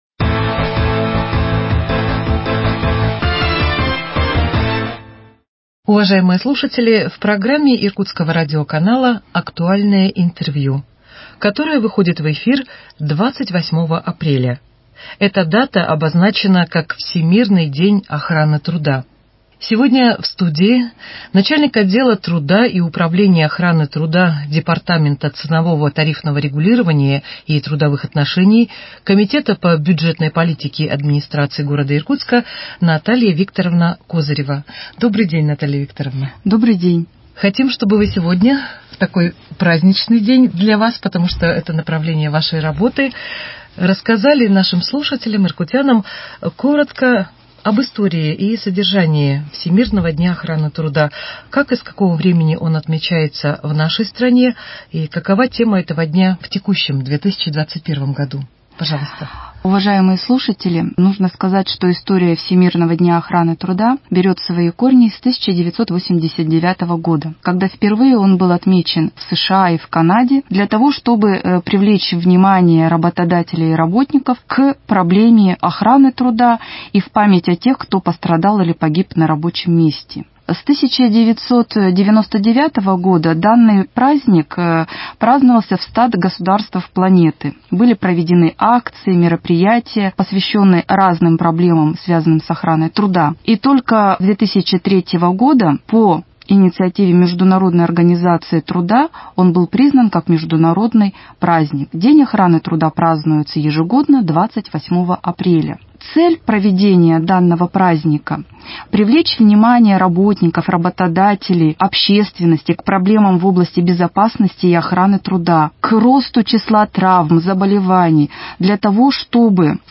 Актуальное интервью: День охраны труда 28.04.2021